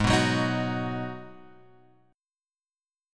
G# Minor 7th